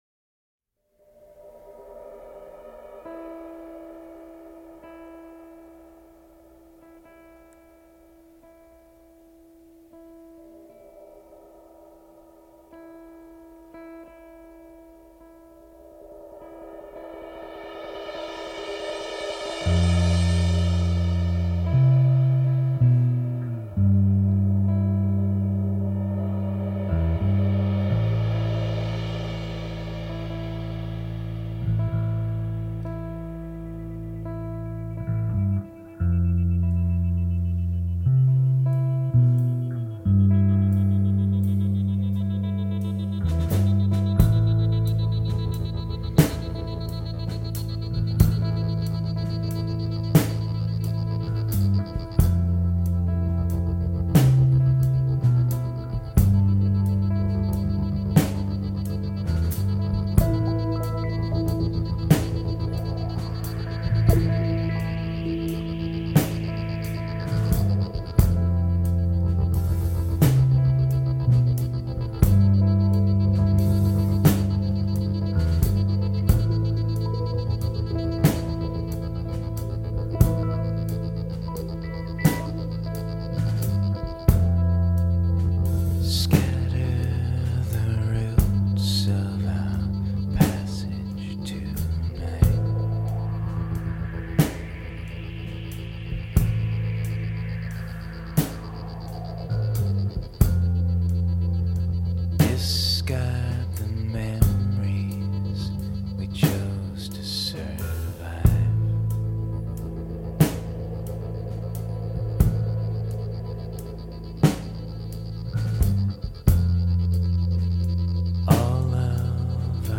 Genres: Post-Rock, Slowcore
Sadcore